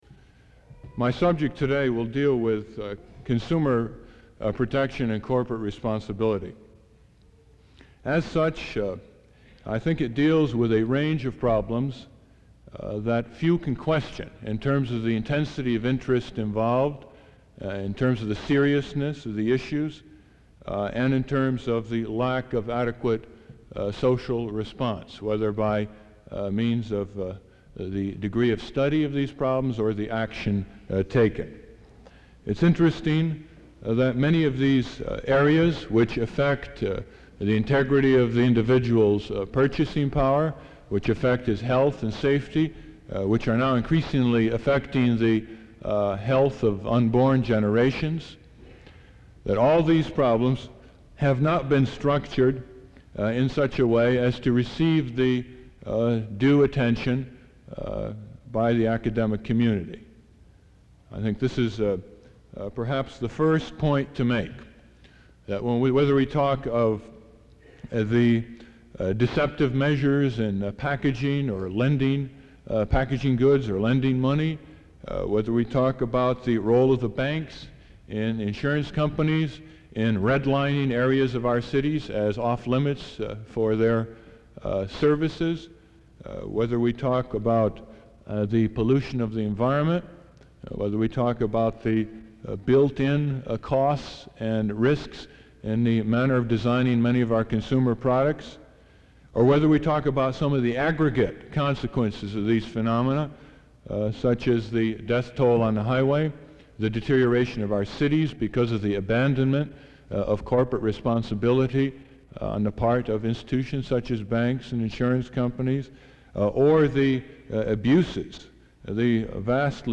Lecture Title
Ralph Nader speaking at Assembly Series in Graham Chapel, November 1969